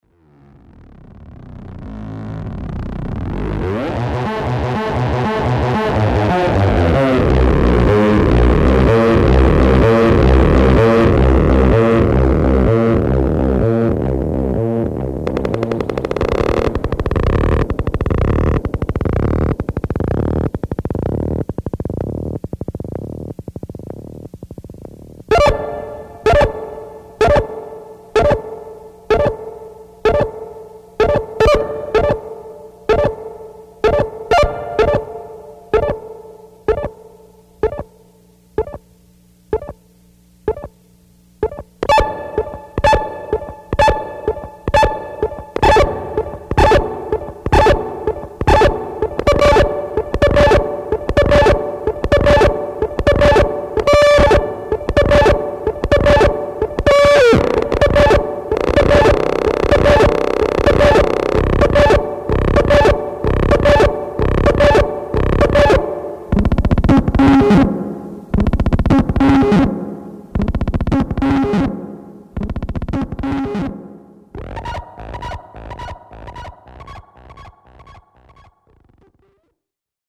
was made with an electronics kit and a delay pedal.